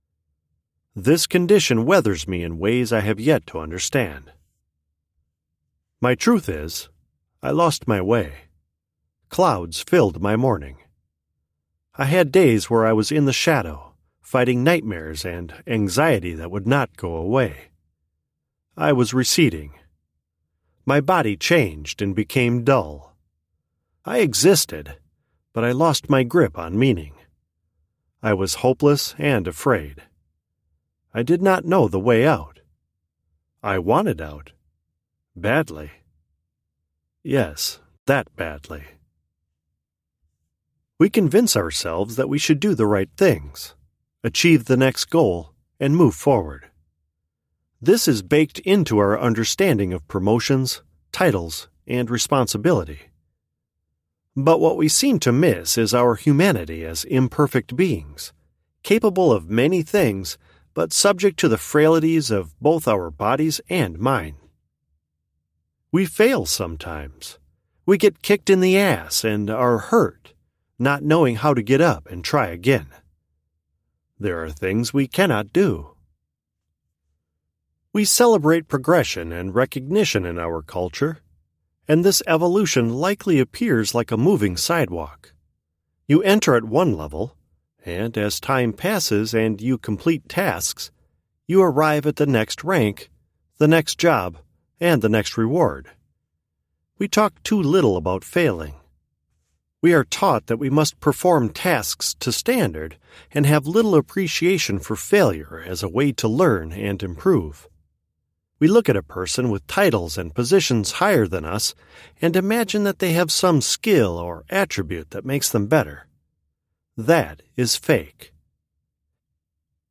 My voice is a rich baritone, a bit gravelly now that I'm in my mid-50s but still nice and strong, dignified but with lots of energy and expression, that is very well-suited to narrative deliveries for projects such as audiobooks, documentaries, explainers, and suitable broadcast-style advertisements.
Audiobooks
Words that describe my voice are baritone, gravelly, expressive.